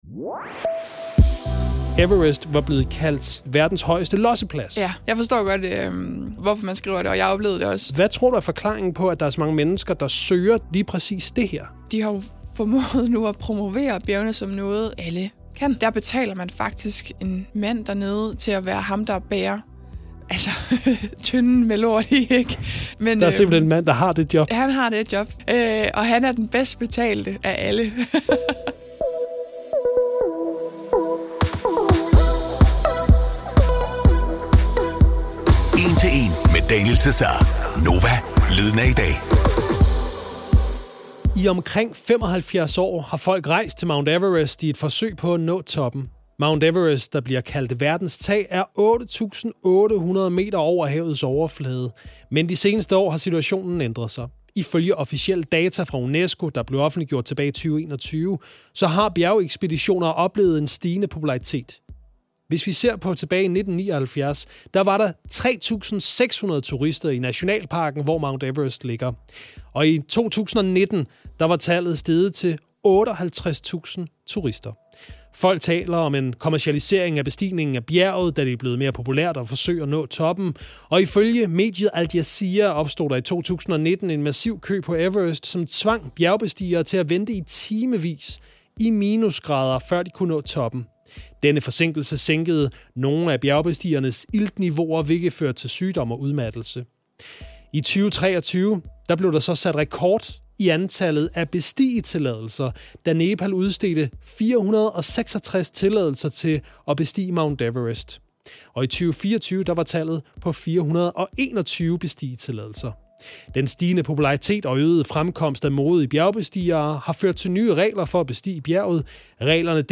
I denne episode tager vi et nærmere kig på det nogen kalder verdens højest losseplads; Mount Everest. Gæst i studiet